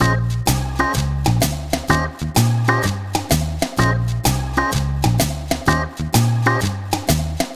Swingerz 1 Full-A#.wav